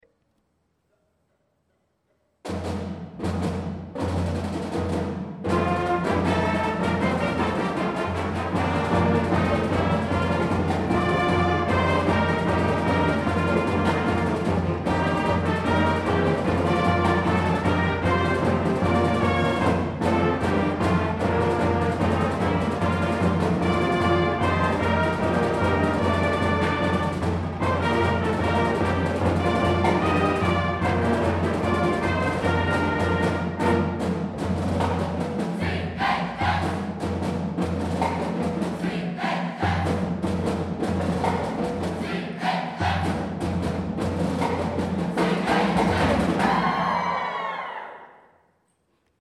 We'll fight forever, CACTUS HIGH Fight Song Audio (Band)